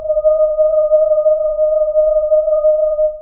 Percussion
alien1_ff.wav